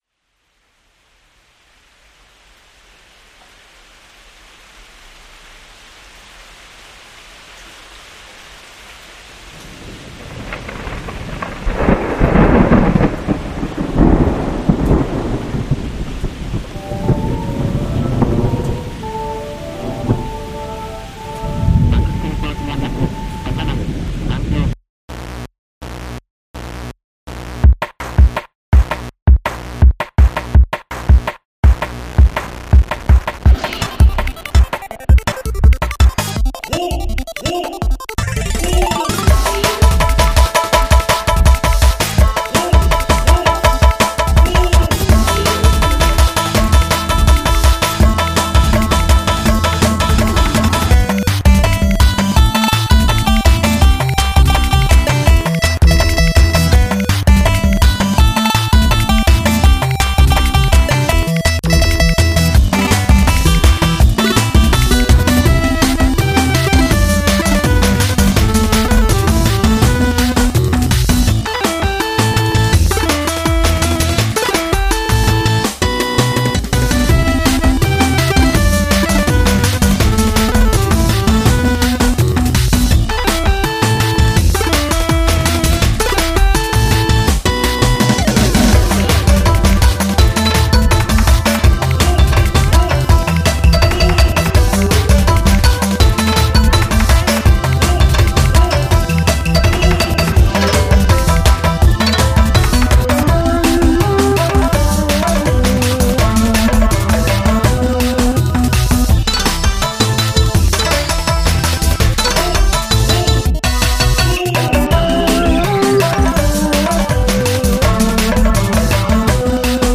Mac speak text
AI Talk
YMCK Magical 8bit Plug